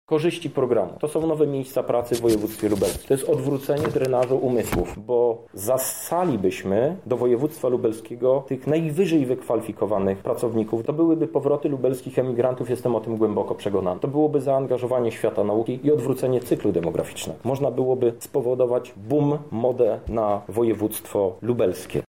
O tym dlaczego ten program jest tak istotny mówi prezes regionalnego PSL poseł Krzysztof Hetman.